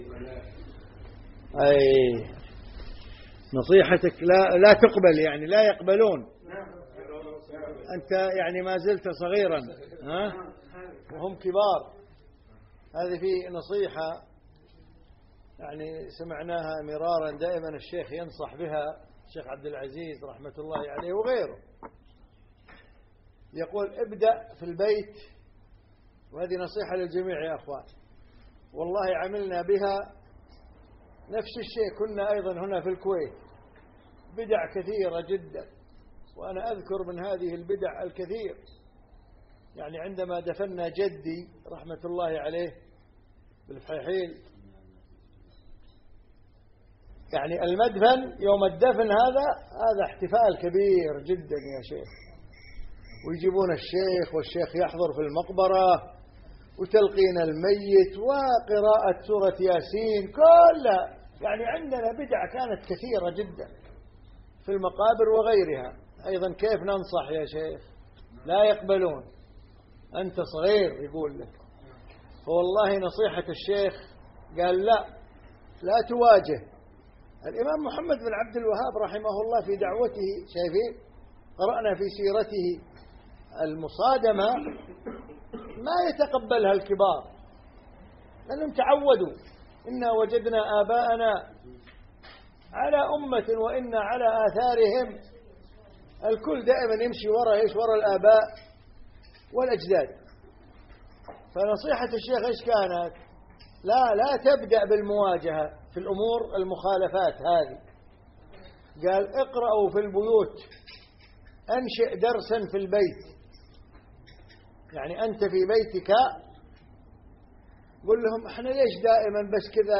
مقتطف من لقاء مع بعض الأخوة من طلبة العلم من أوروبا رابط المحاضرة http //drosq8 com/play php?catsmktba=6212